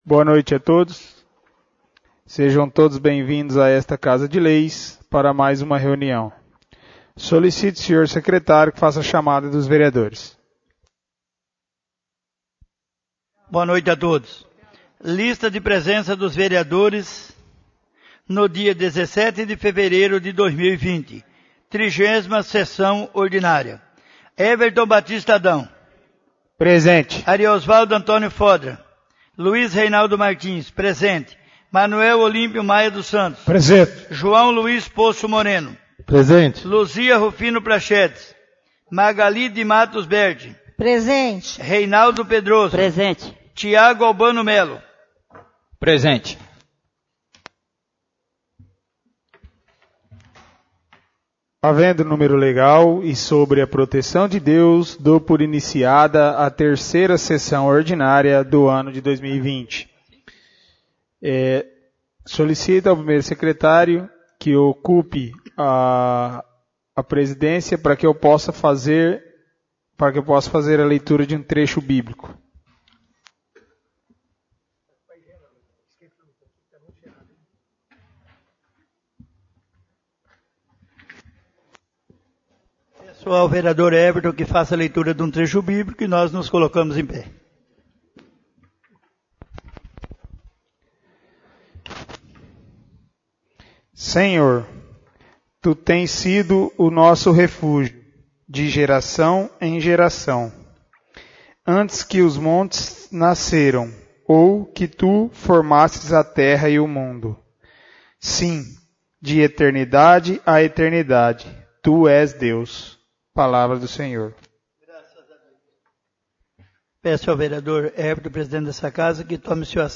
3° Sessão Ordinária de 2020 — CÂMARA MUNICIPAL